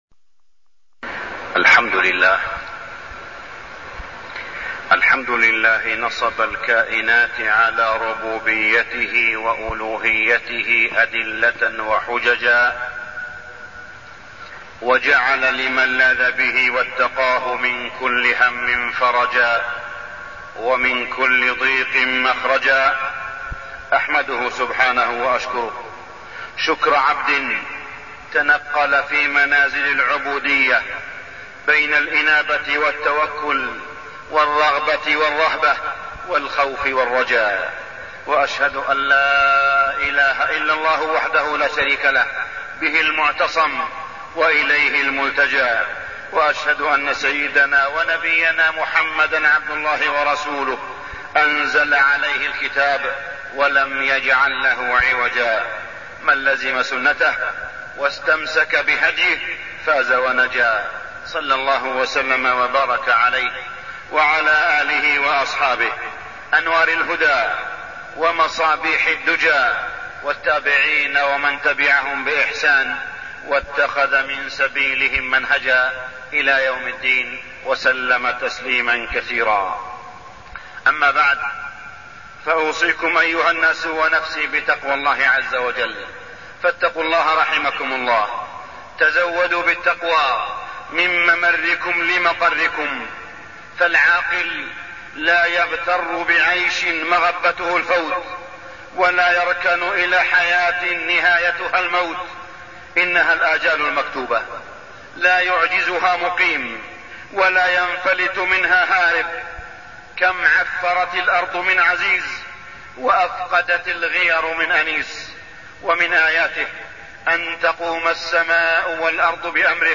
تاريخ النشر ٣ ربيع الثاني ١٤٢٠ هـ المكان: المسجد الحرام الشيخ: معالي الشيخ أ.د. صالح بن عبدالله بن حميد معالي الشيخ أ.د. صالح بن عبدالله بن حميد قضية المرأة المسلمة